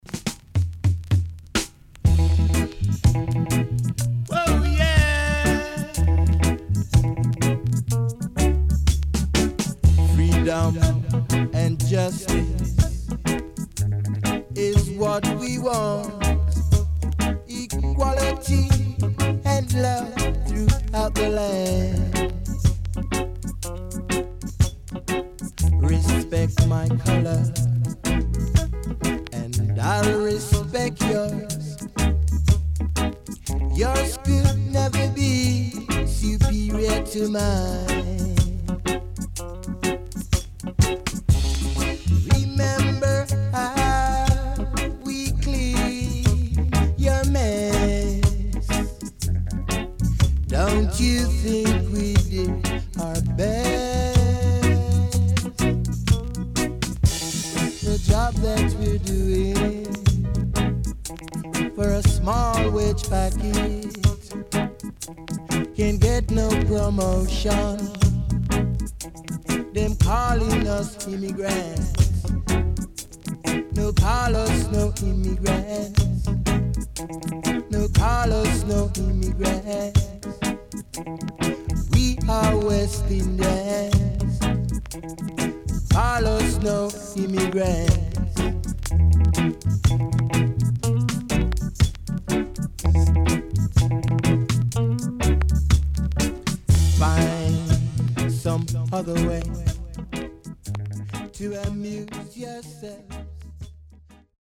CONDITION SIDE A:VG(OK)
【12inch】
Killer Lovers & Nice Vocal
SIDE A:所々ノイズ入ります。